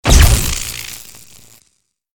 LB_capacitor_discharge_3.ogg